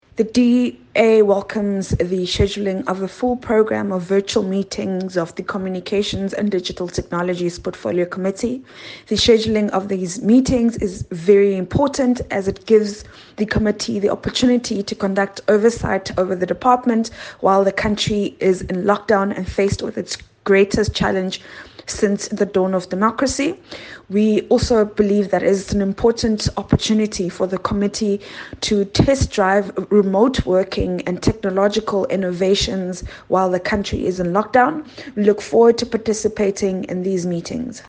soundbite by DA Shadow Minister of Communications and Digital Technologies, Phumzile Van Damme MP